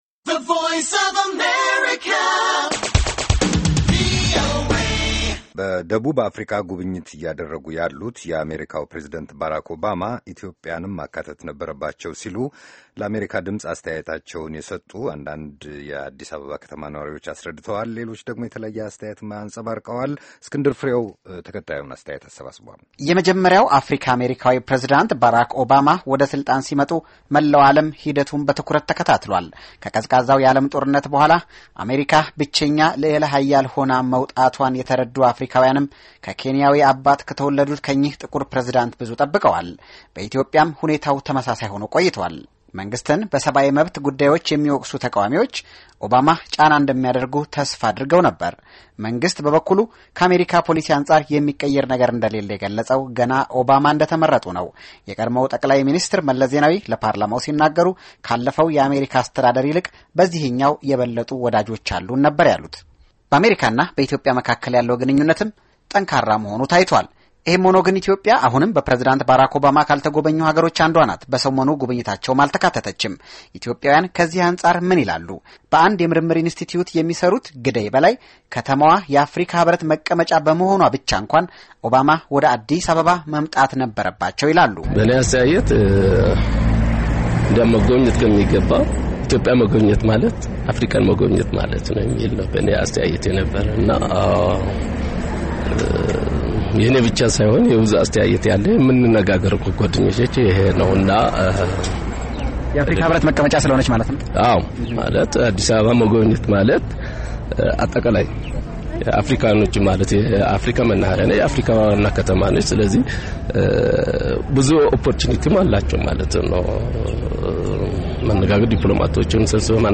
Addis Ababa, voxpop on Obama's Africa visit